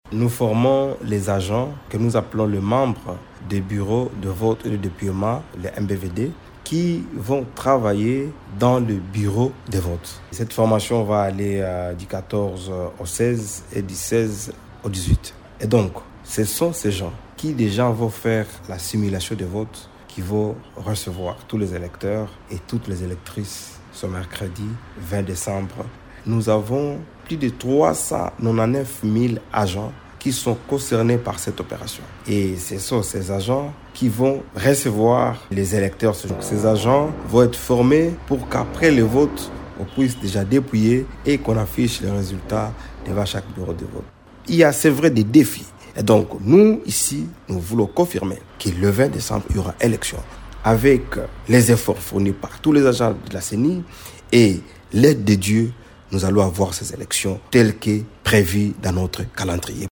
Le rapporteur-adjoint de la CENI, Paul Muhindo l’a affirmé dans un entretien accordé à Radio Okapi.